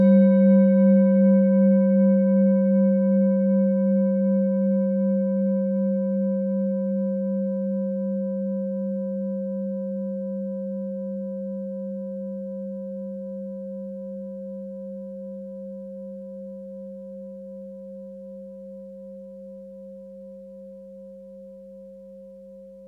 Klangschale Orissa Nr.21
Klangschale-Durchmesser: 18,0cm
Sie ist neu und wurde gezielt nach altem 7-Metalle-Rezept in Handarbeit gezogen und gehämmert.
(Ermittelt mit dem Filzklöppel)
klangschale-orissa-21.wav